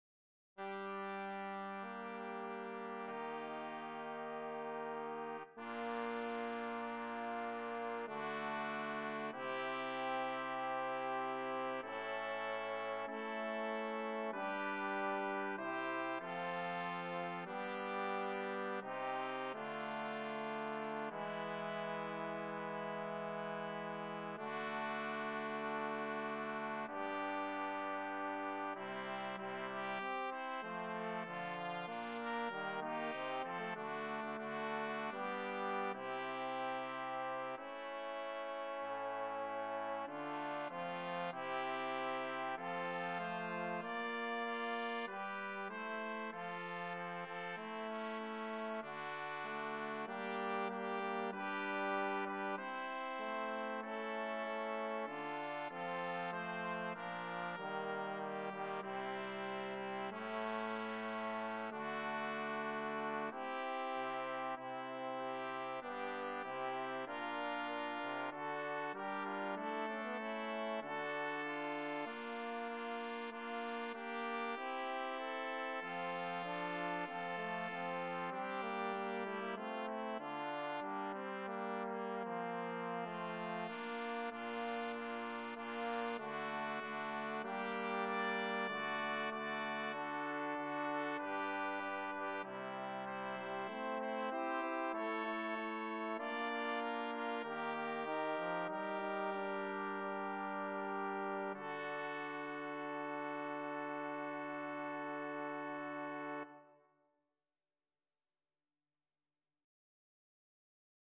BRASS QUARTET
FOR 2 TRUMPETS, 2 TROMBONES